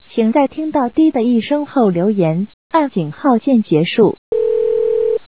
留言提示音.wav